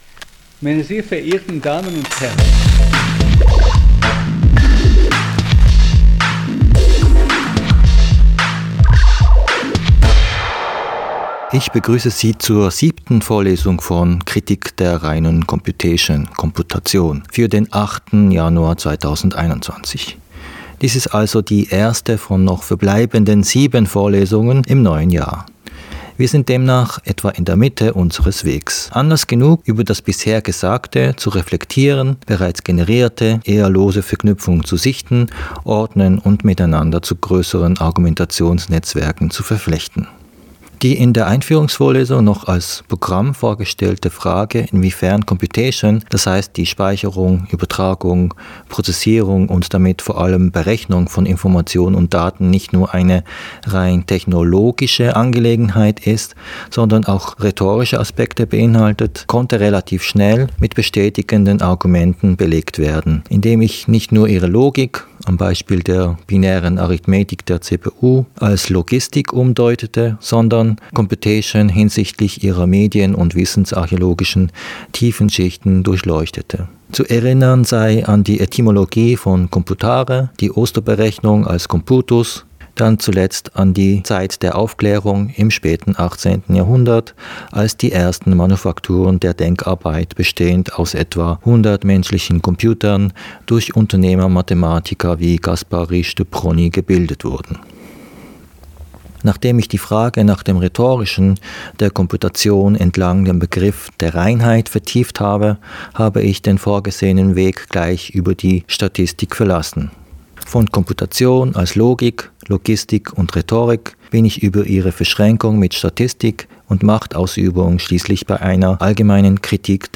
Podcastvorlesung